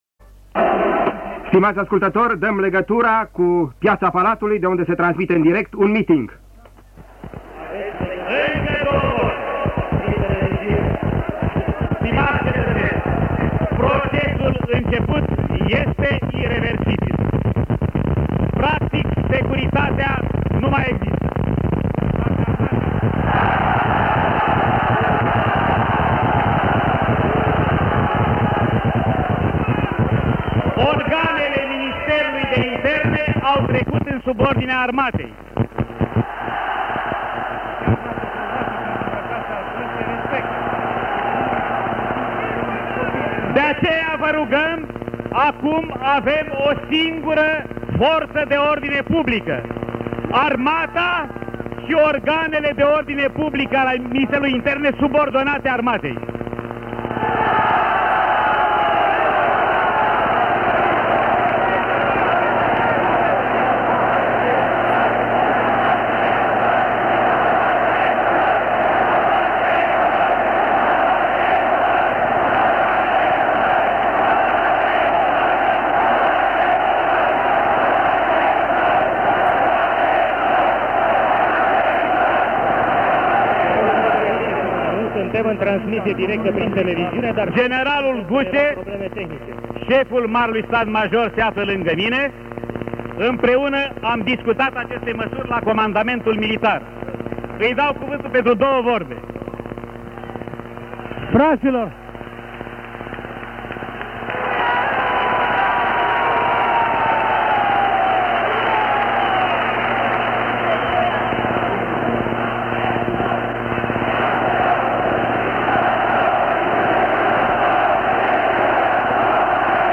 La radio și la televiziune sunt transmise secvențe din piața Palatului în care se disnting vocile celor care transmiteau mesaje cu apel la calm și liniște către populație acompaniate pe fundal de zgomotul dur produs de gloanțele armelor.
La Mitingul din Piața Palatului din 22 decembrie a vorbit, printre alții cel care avea să fie primul președinte al României după căderea comunismului, Ion Iliescu: